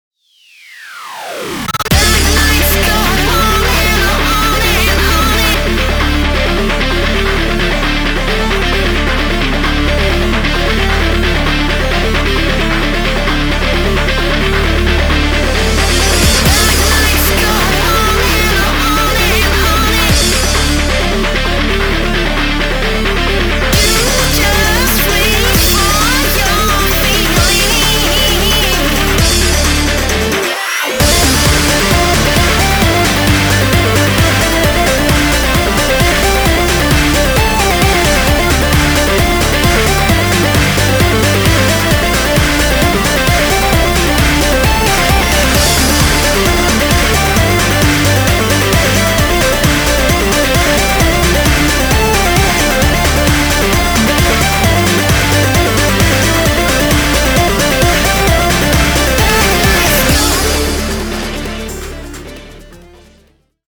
Electro、Tech、Hard Style等を収録し、Vocal曲も2曲収録しております。